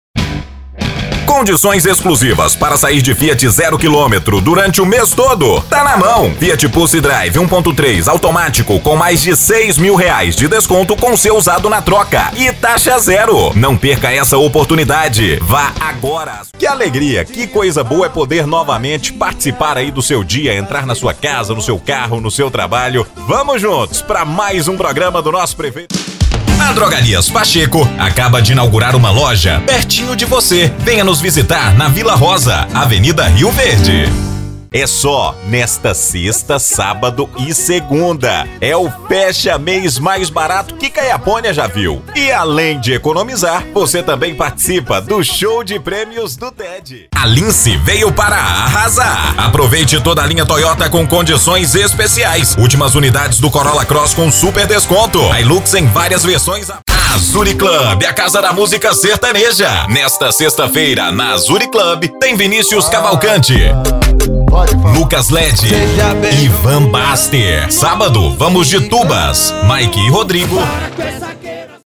Spot Comercial
Estilo(s):
Animada